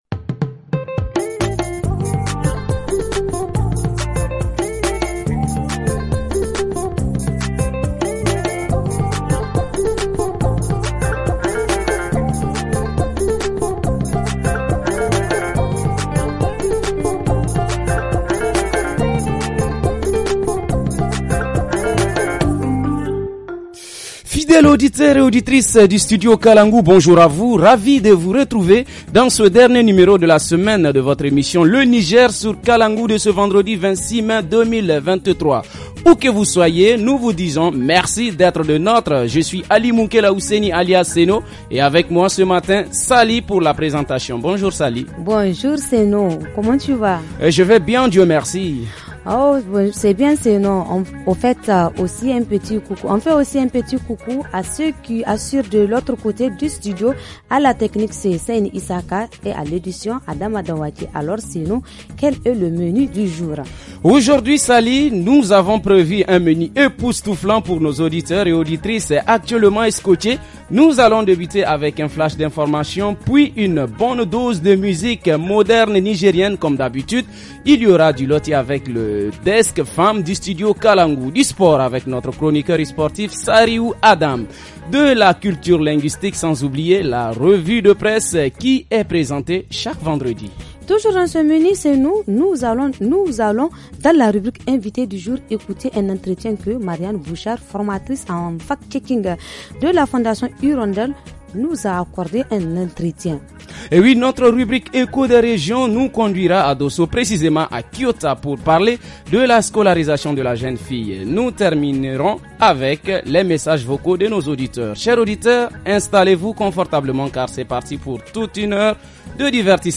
–invitée du jour : Entretien
–Reportage région : Nous allons à kiota, pour parler de la scolarisation de la jeune fille ;